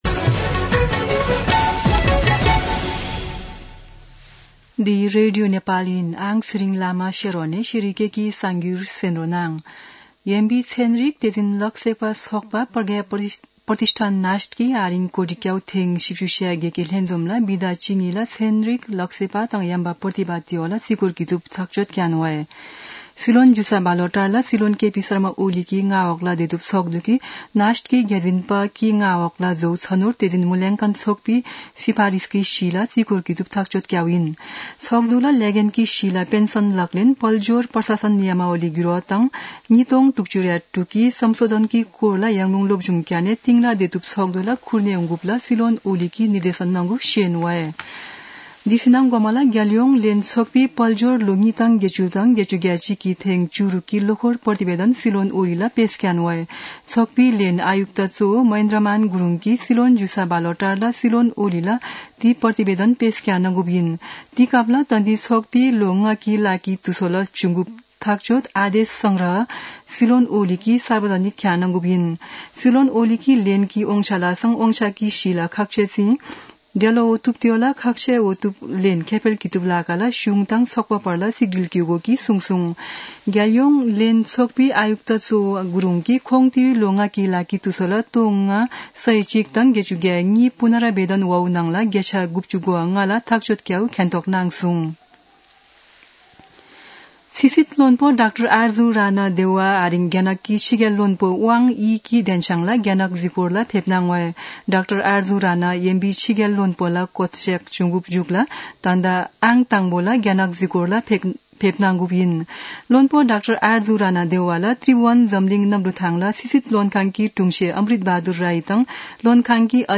शेर्पा भाषाको समाचार : १४ मंसिर , २०८१
4-pm-Sherpa-news-1-6.mp3